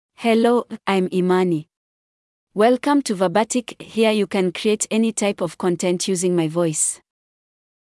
FemaleEnglish (Tanzania)
Imani is a female AI voice for English (Tanzania).
Voice sample
Female
Imani delivers clear pronunciation with authentic Tanzania English intonation, making your content sound professionally produced.